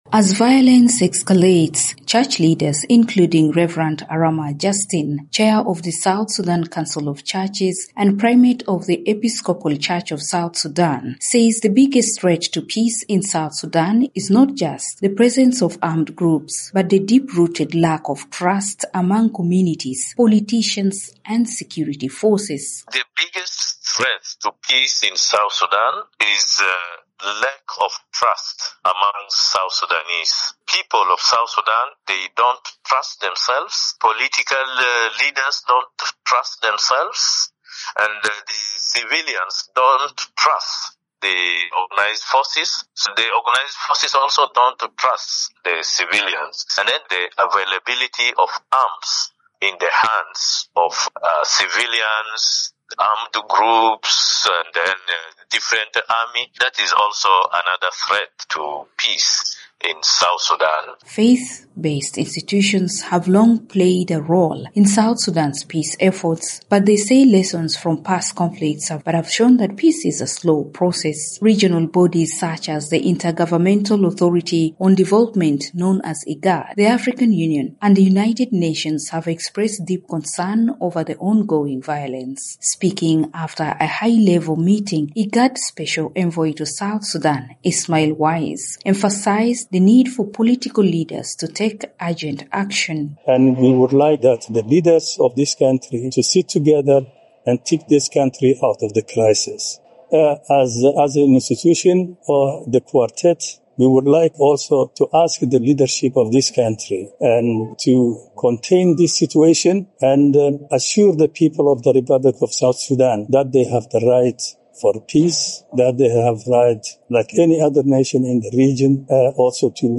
in Juba reports on the growing concerns.